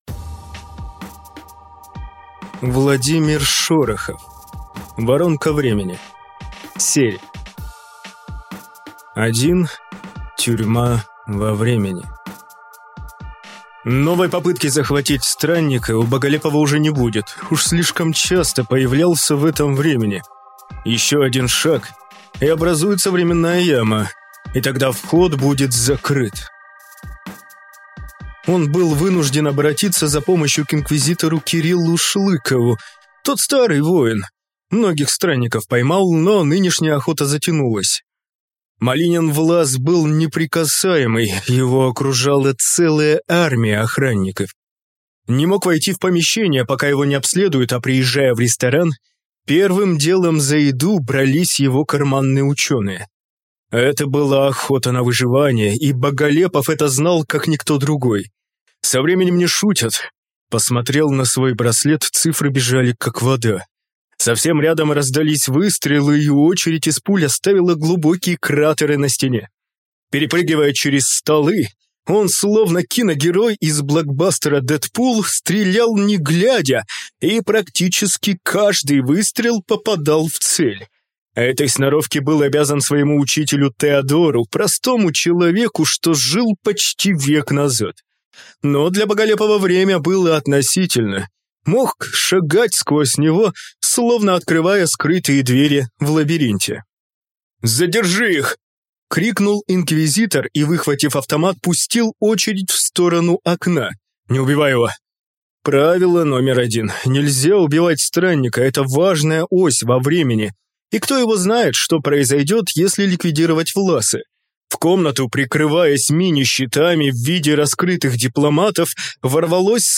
Аудиокнига Воронка времени | Библиотека аудиокниг